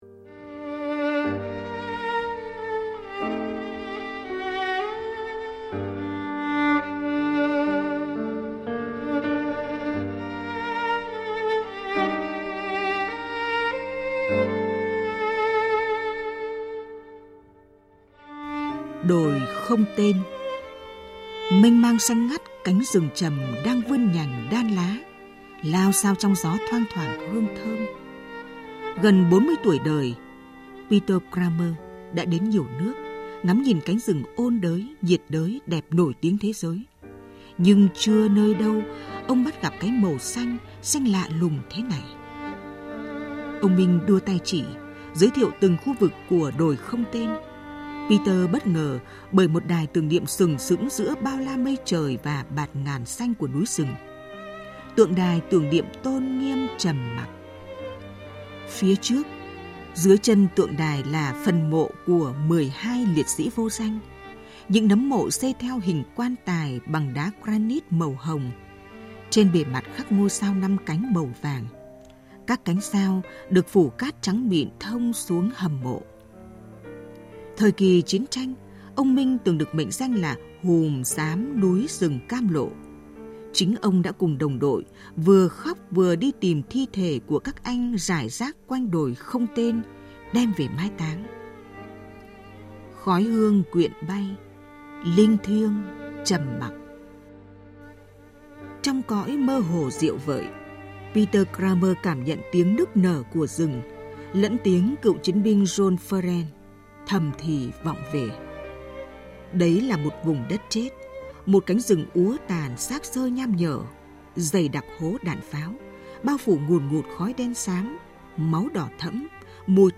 Nghe truyện tại đây